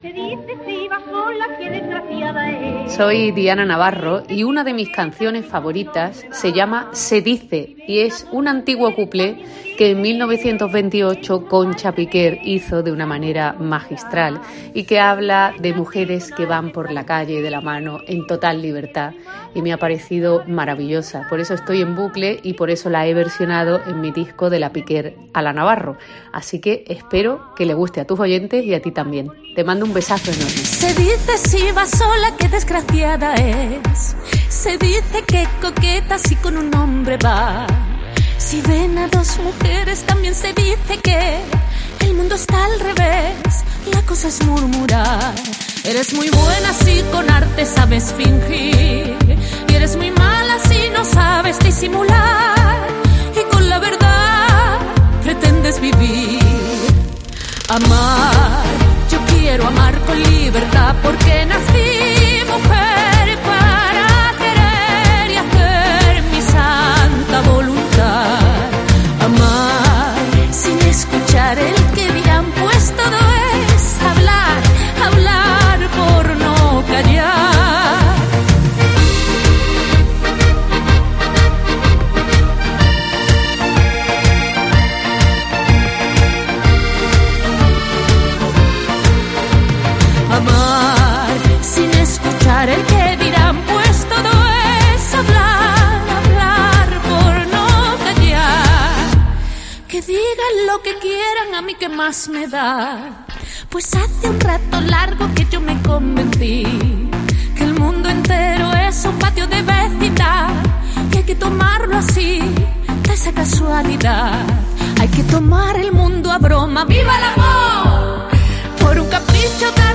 La cantante malagueña
del que hace una versión muy personal
un antiguo cuplé